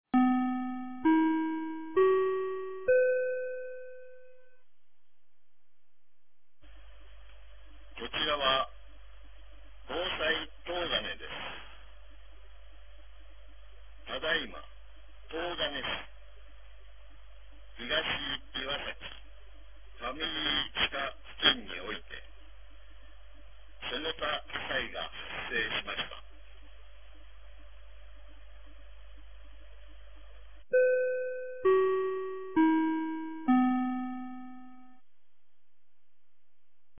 2025年02月01日 09時17分に、東金市より防災行政無線の放送を行いました。